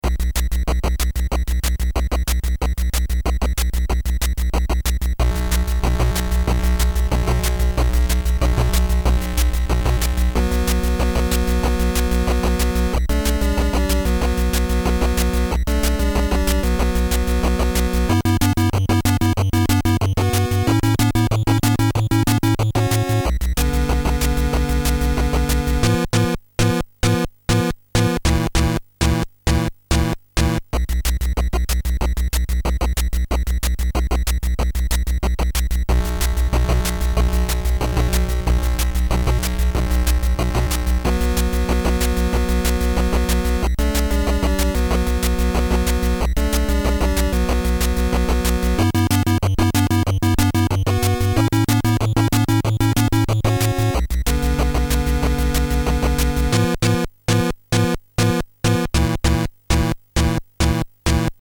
Jetzt auch mit Musik vom Bossfight!